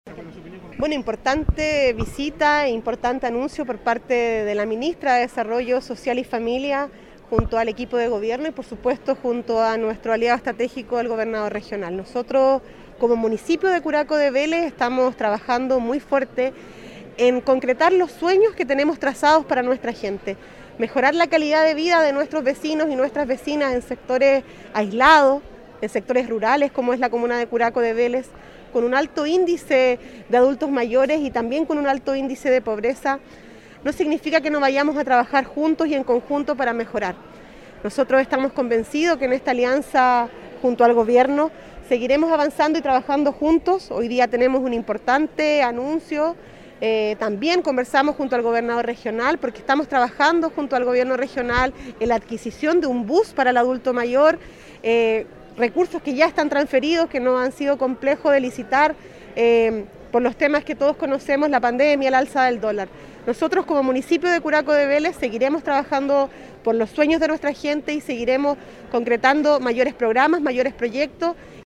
La alcaldesa Javiera Yáñez valoró la visita ministerial y los anuncios efectuados en la ceremonia de inauguración del CEDIAM curacano.